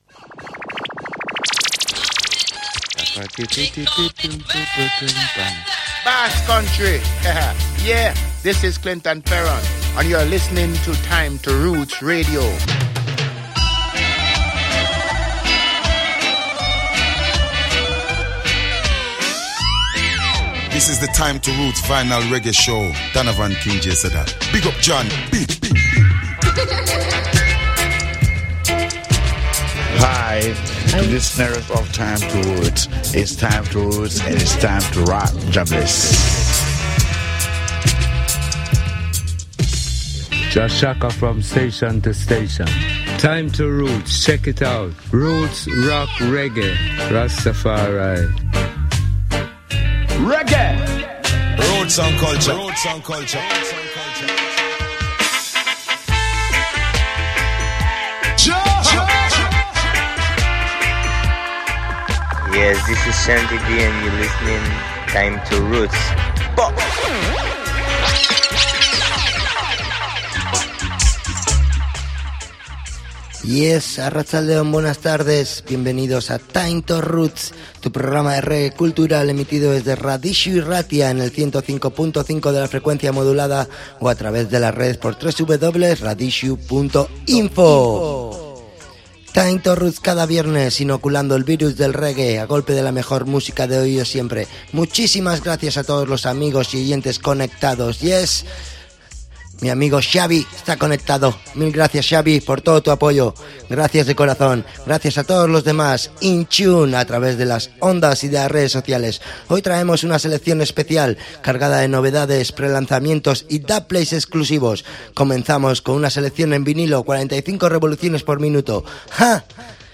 �?� Foundation To Roots Vinyl Selection �?� News, Pre-Releases & Dubplates